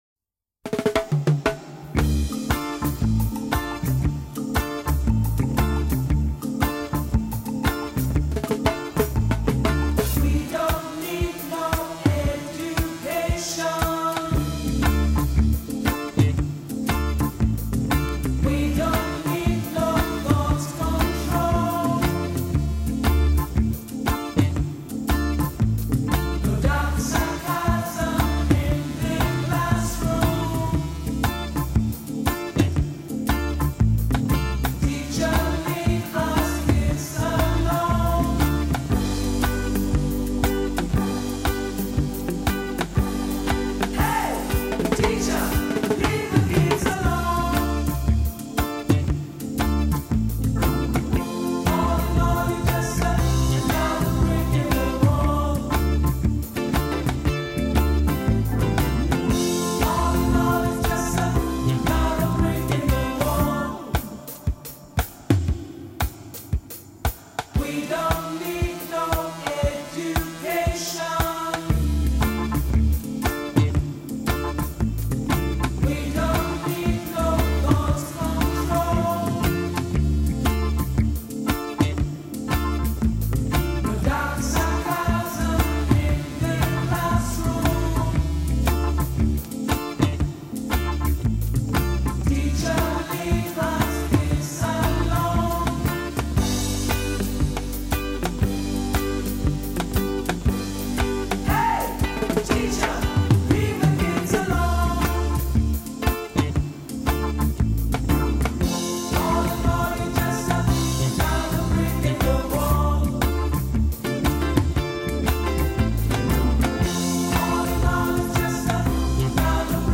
Reggae Version!